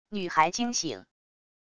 女孩惊醒wav音频